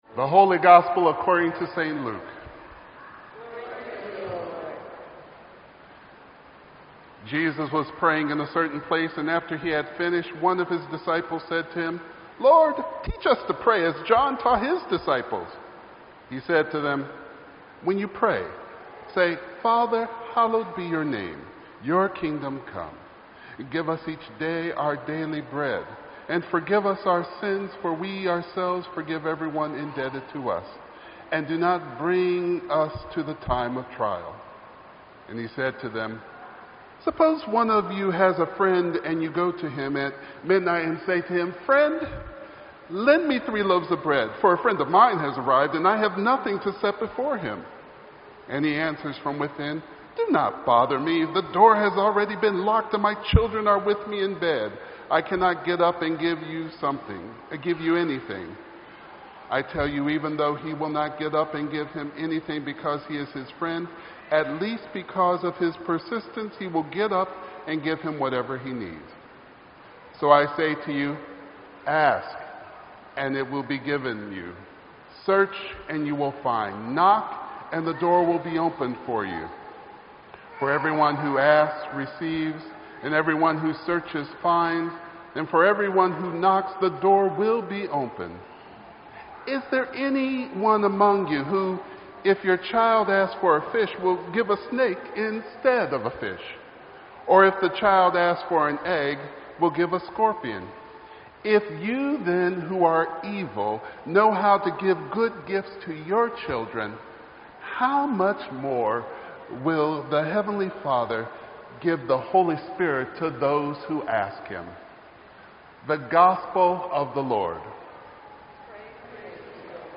Sermon_7_24_16.mp3